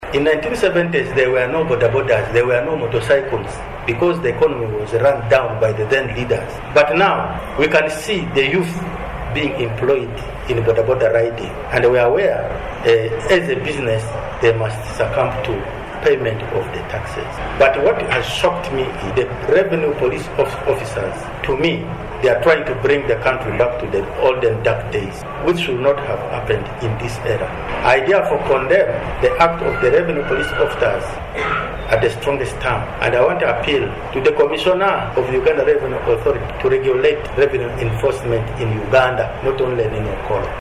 During a press briefing, David Agbara, the Okollo Town Council LC3 chairman, strongly condemned the perceived high-handedness of URA field officers, blaming their operations for the senseless killing of innocent locals.